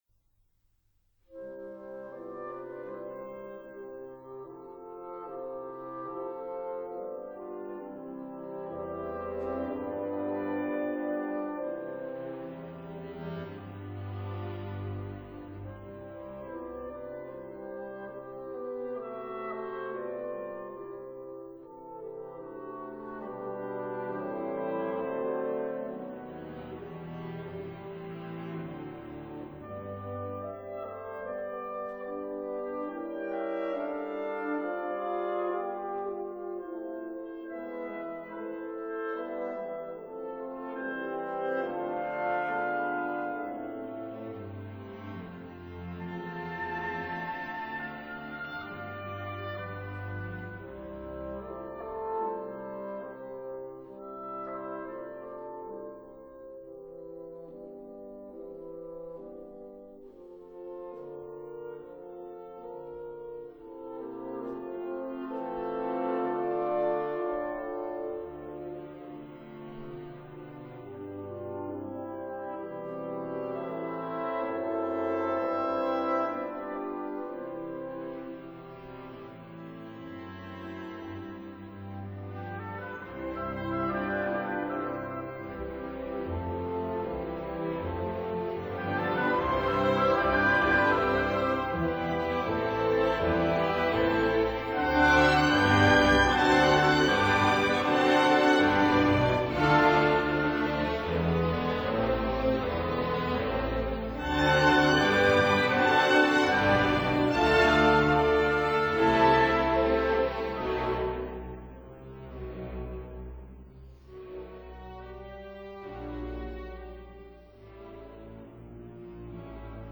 01 - 04 Symphony No.3 in F major, op.90 [37'15]